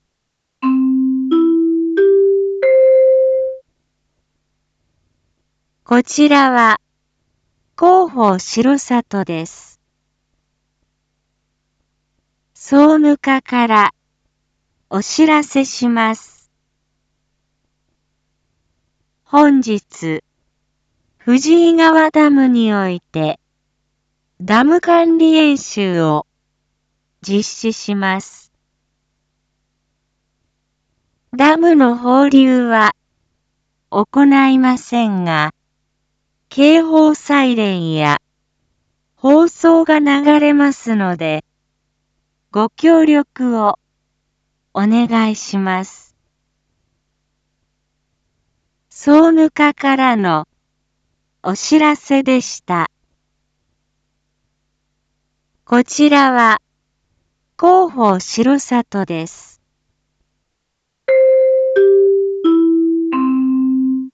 Back Home 一般放送情報 音声放送 再生 一般放送情報 登録日時：2023-05-10 07:01:05 タイトル：藤井川ダム管理演習について インフォメーション：こちらは、広報しろさとです。